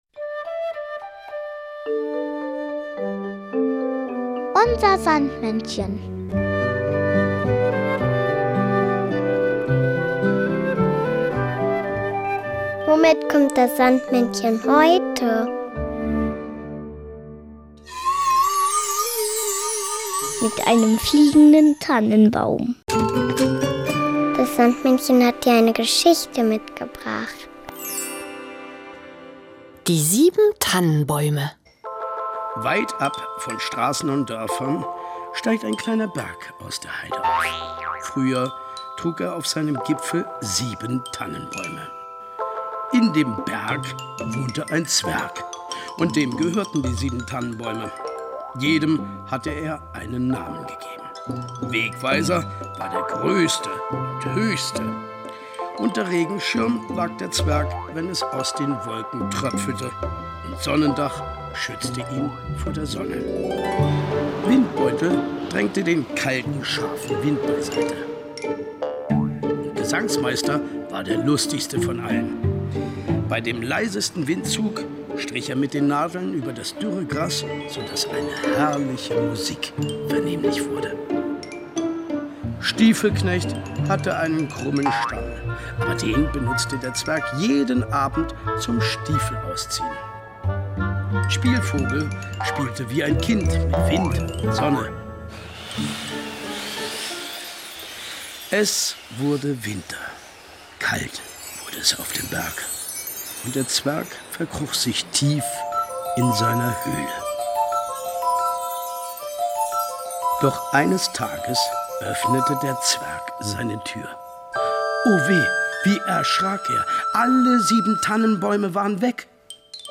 Märchen: Die sieben Tannenbäume